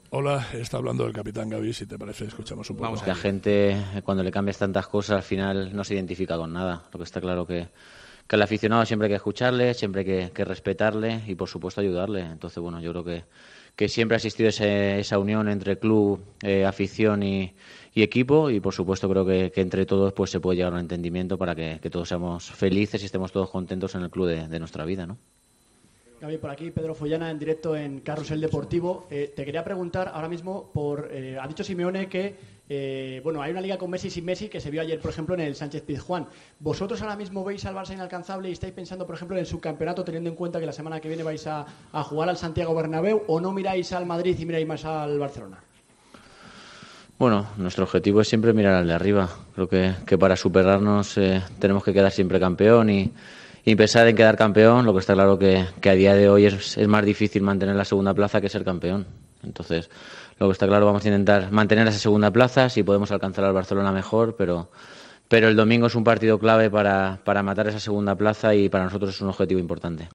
Siempre ha existido esa unión entre club, afición y equipo y entre todos se puede llegar a un entendimiento para que todos seamos felices en el club de nuestra vida", explicó en rueda de prensa en el Wanda Metropolitano.